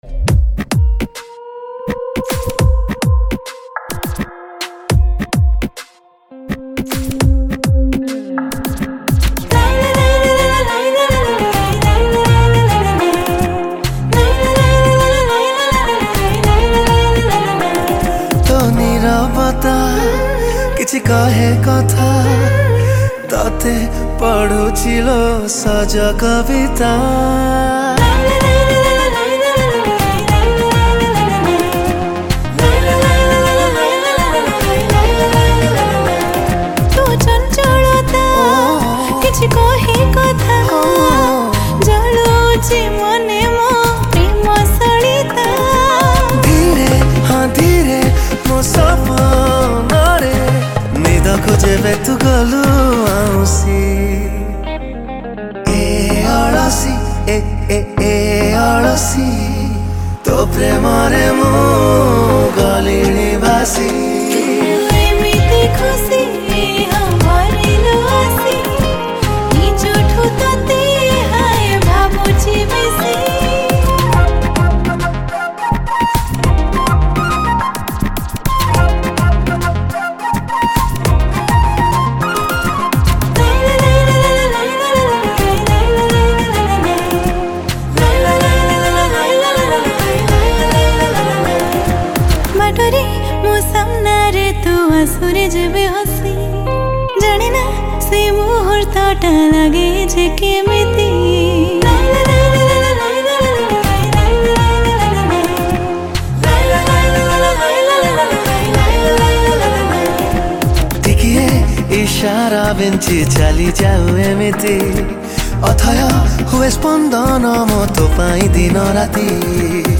Romantic Song